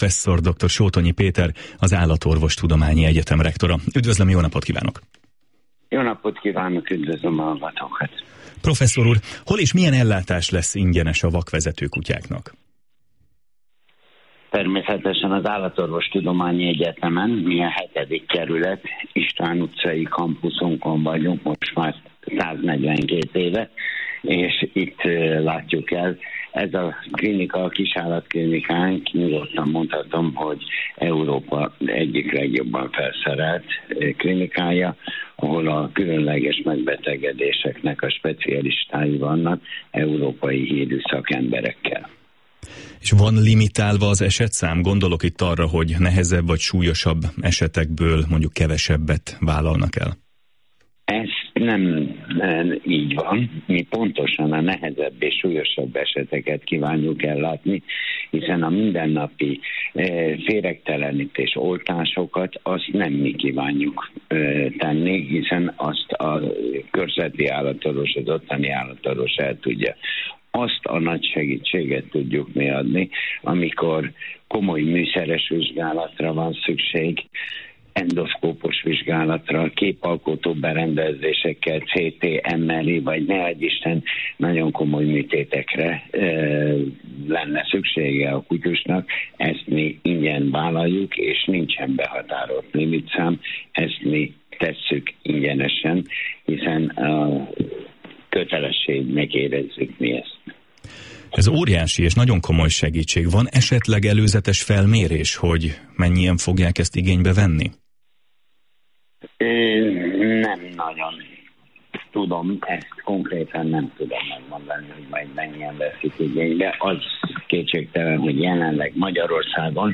egyetemünk rektorával készült interjút